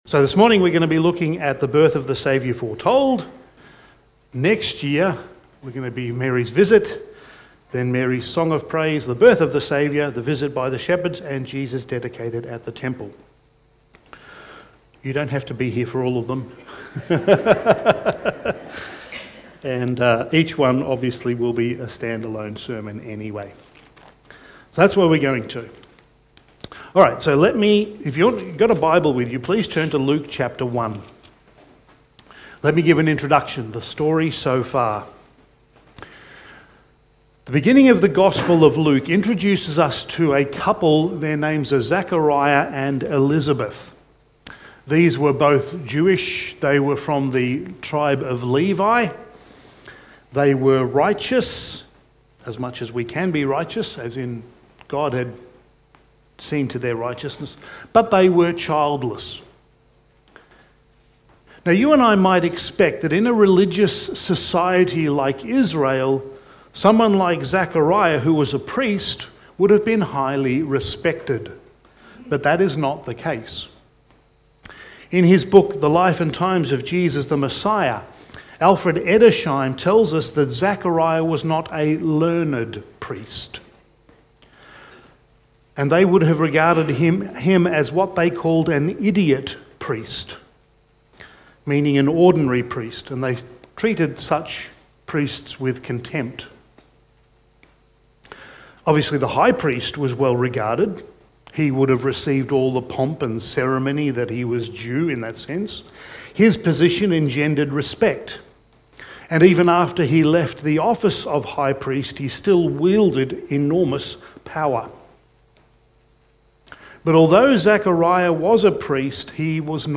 Preacher
Passage: Luke 1:26-38 Service Type: Special Event